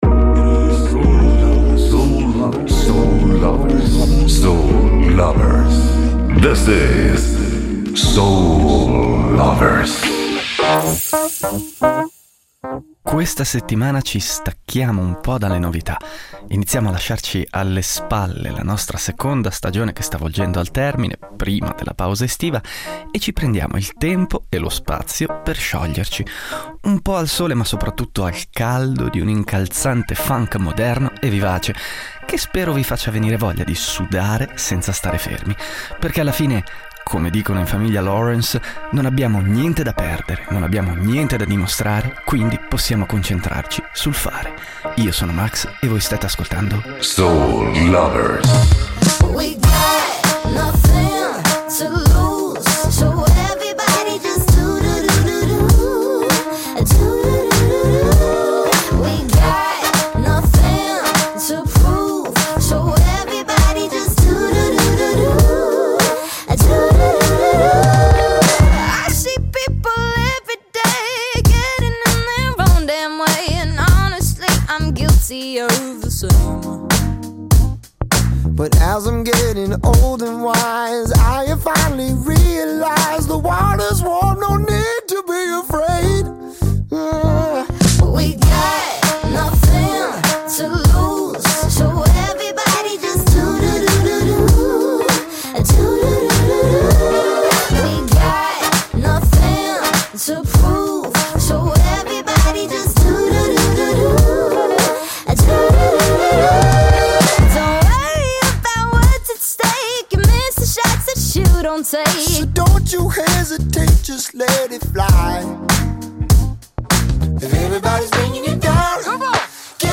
Questa settimana ci stacchiamo un po’ dalle novità, iniziamo a lasciarci alle spalle la nostra seconda stagione e ci prendiamo il tempo e lo spazio per scioglierci, un po’ al sole ma soprattutto al caldo di un incalzante funk moderno e vivace, che spero vi faccia venire voglia di sudare senza stare fermi.